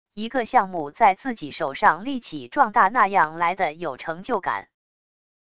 一个项目在自己手上立起壮大那样来的有成就感wav音频生成系统WAV Audio Player